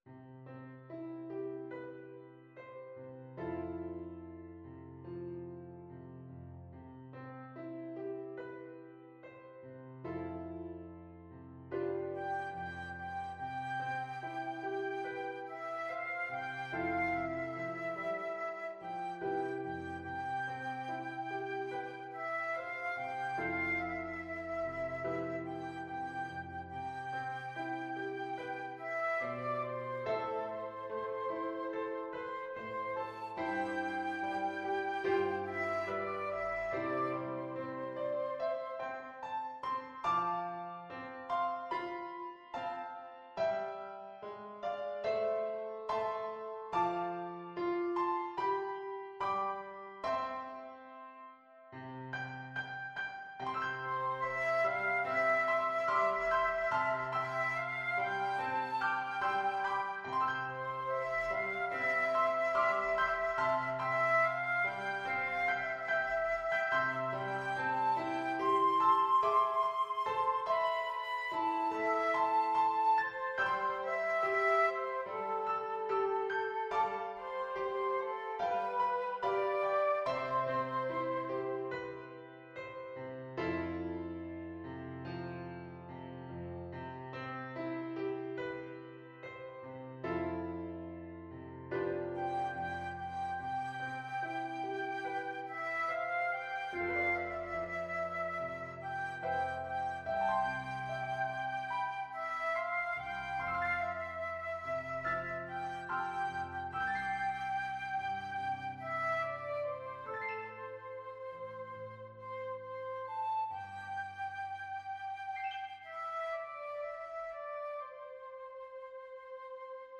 Flute
C major (Sounding Pitch) (View more C major Music for Flute )
4/4 (View more 4/4 Music)
Molto espressivo =c.72
Classical (View more Classical Flute Music)
Welsh